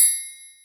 [ACD] - BMF Triangle.wav